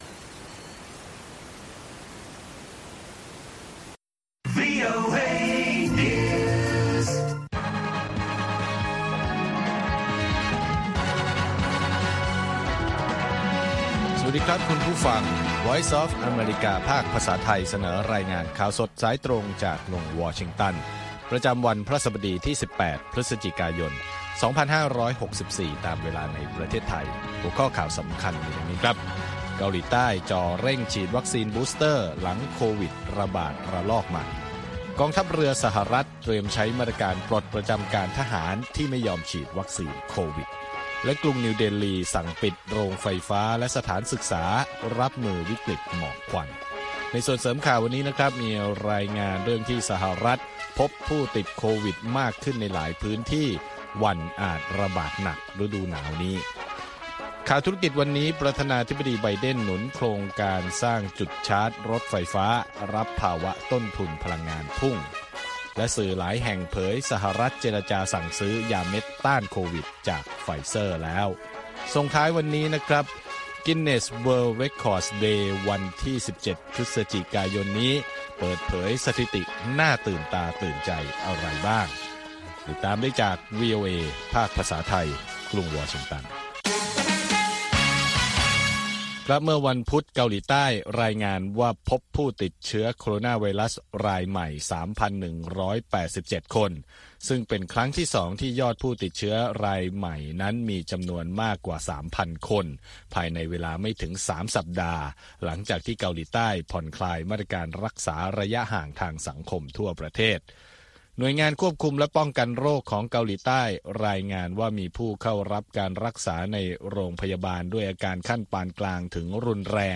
ข่าวสดสายตรงจากวีโอเอ ภาคภาษาไทย 6:30 – 7:00 น. ประจำวันพฤหัสบดีที่ 18 พฤศจิกายน 2564 ตามเวลาในประเทศไทย